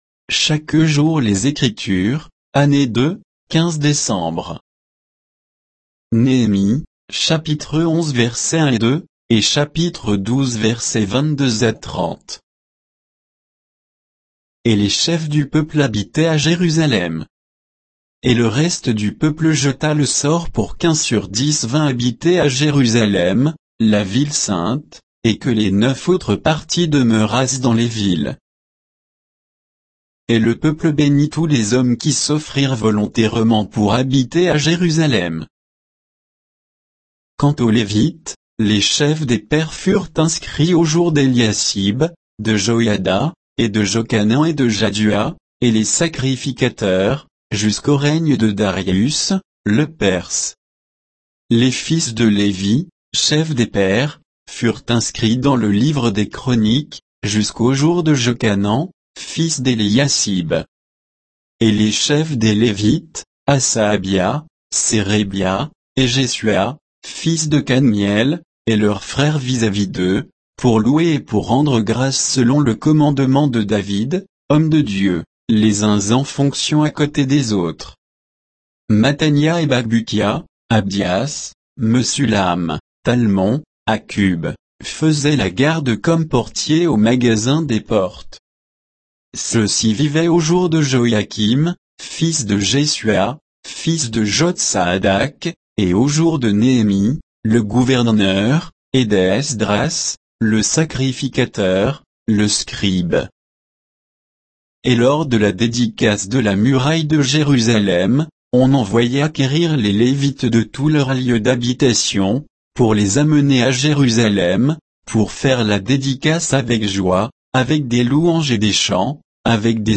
Méditation quoditienne de Chaque jour les Écritures sur Néhémie 11